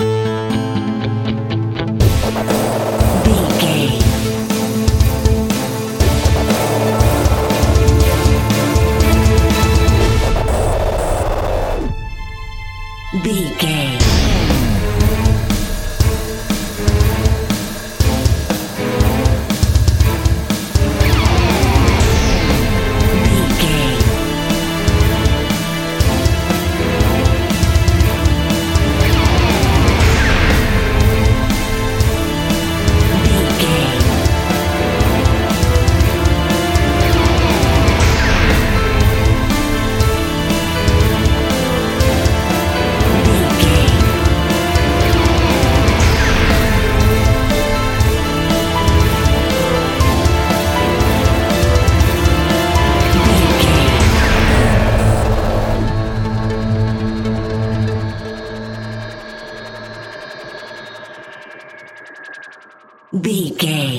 Video Game Trailer
In-crescendo
Thriller
Aeolian/Minor
Fast
tension
ominous
dark
dramatic
eerie
strings
brass
percussion
synthesiser
drums
electric guitar
wood wind